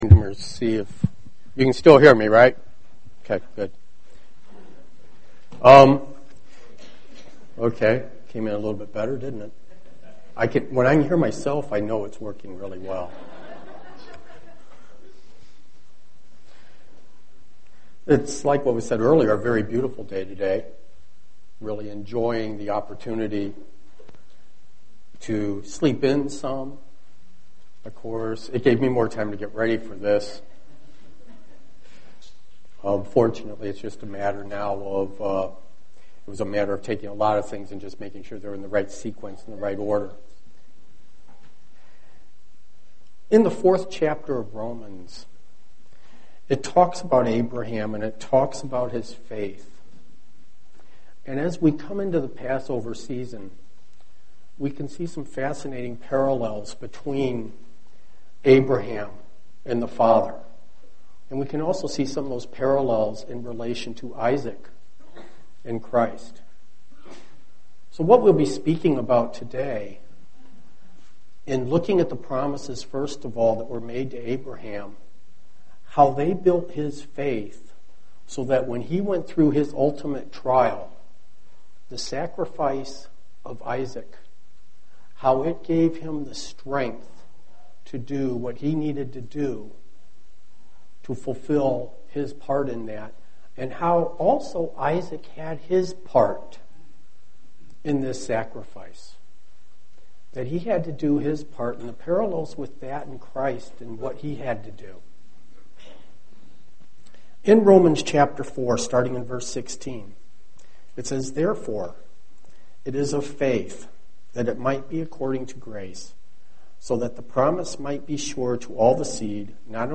Print Discover the parallels between Isaac and Jesus Christ UCG Sermon Studying the bible?